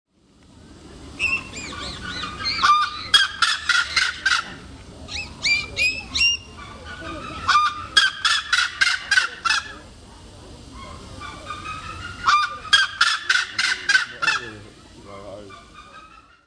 La grue | Université populaire de la biosphère
Elle craquette, claquette, glapit, trompette
Grues.mp3